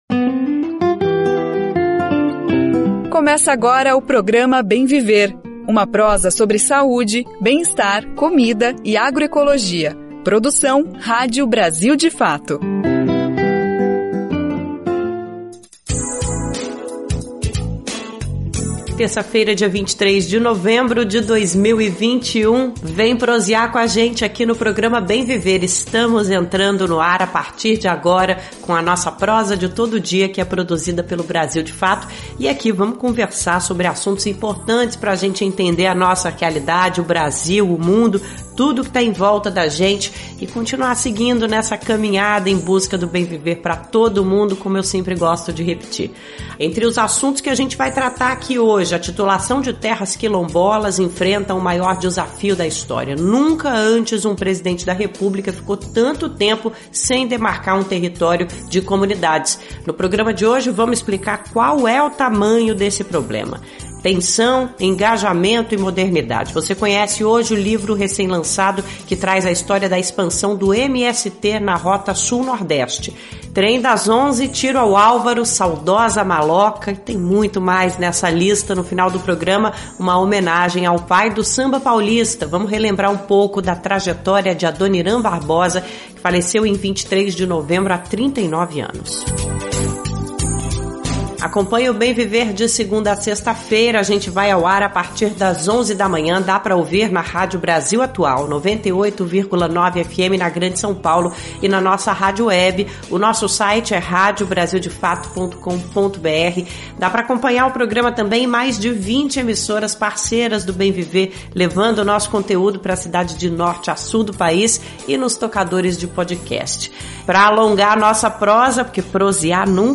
Conversa Bem Viver